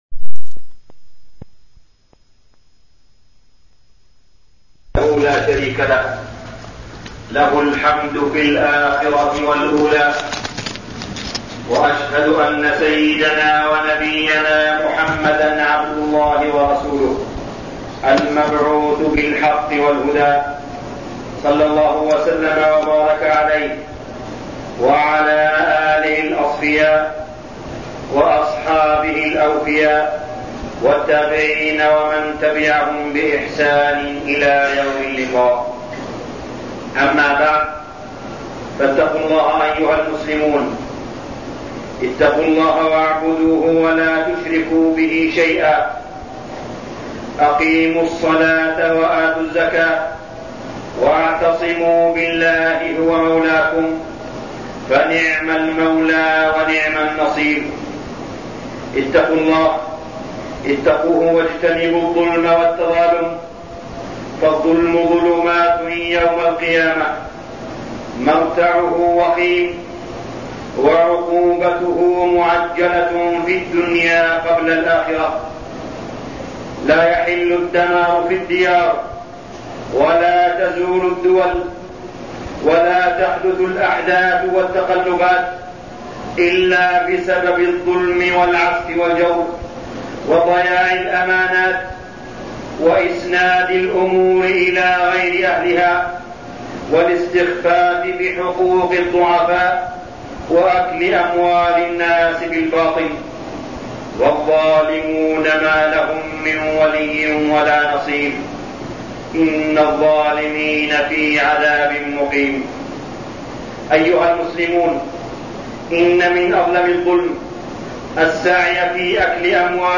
تاريخ النشر ١٨ محرم ١٤٠٨ هـ المكان: المسجد الحرام الشيخ: معالي الشيخ أ.د. صالح بن عبدالله بن حميد معالي الشيخ أ.د. صالح بن عبدالله بن حميد الرشوة